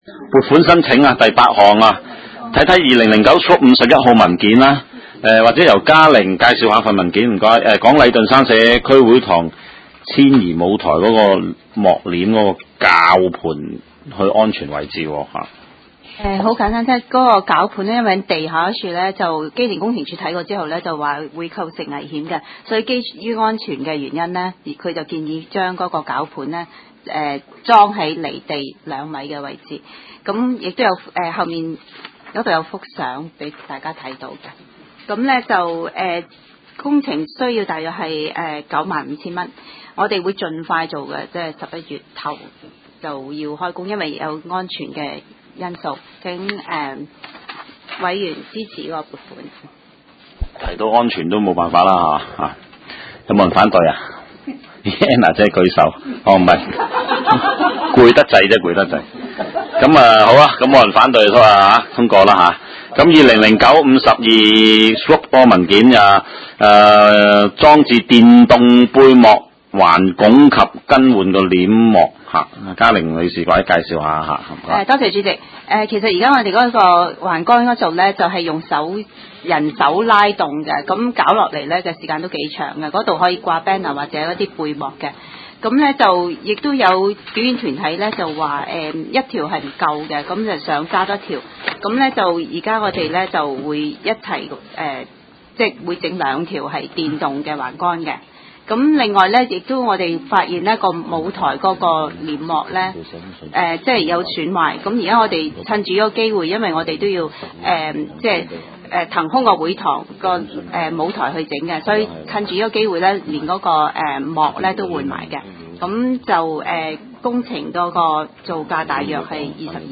地區工程及設施管理委員會第十二次會議
灣仔民政事務處區議會會議室